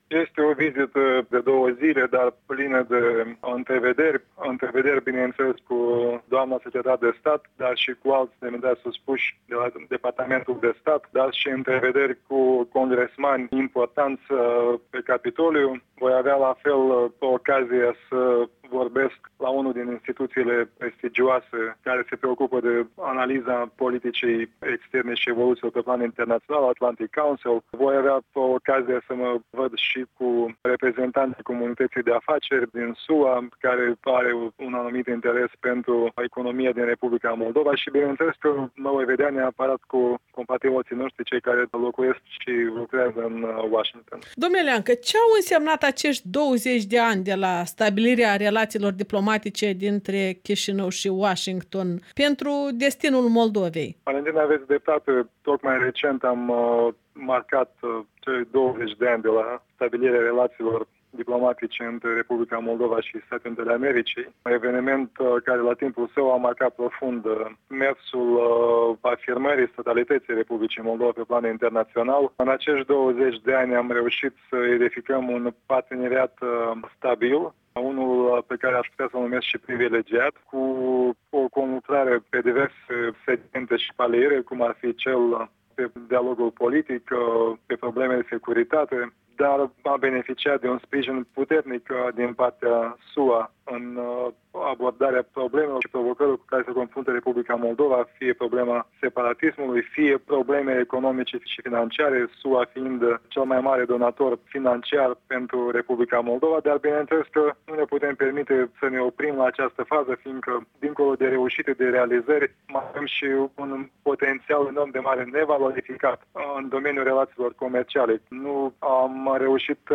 Şeful diplomației moldovene a răspuns întrebărilor Europei Libere.